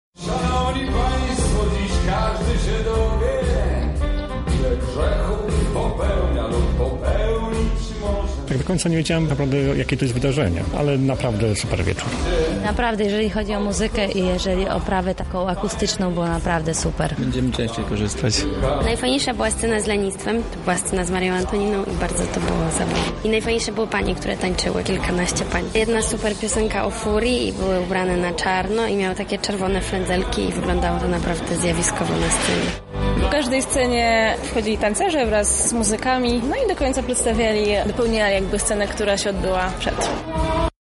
O wrażeniach opowiedzieli uczestnicy.